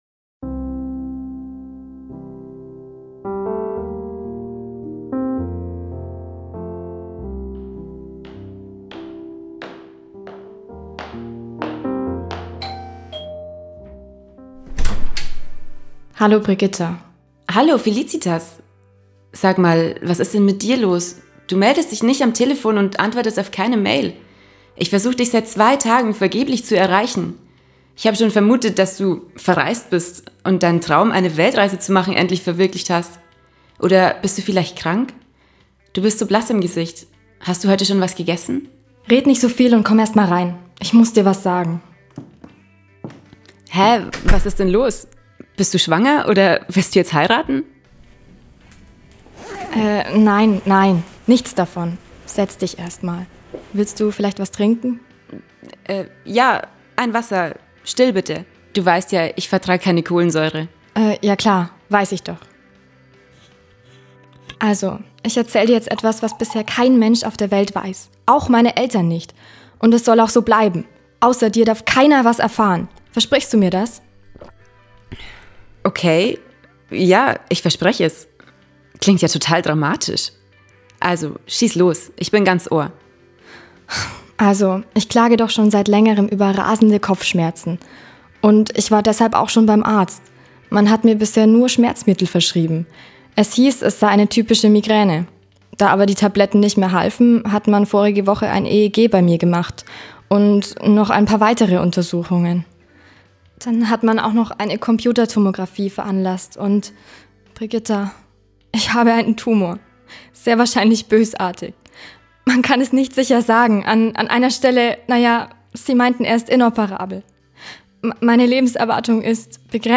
Hoerspiel_EinTraumWirdWahr.mp3